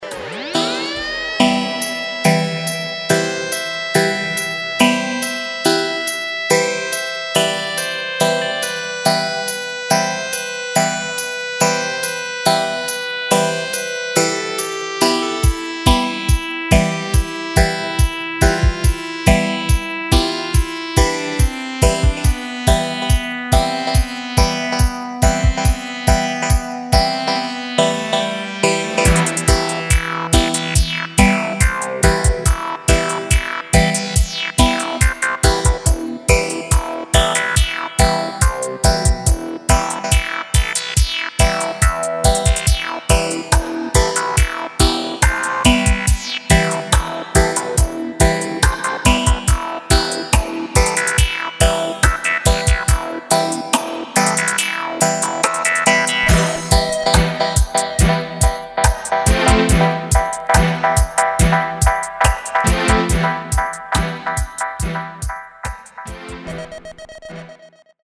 Heavy & Tuff Riddim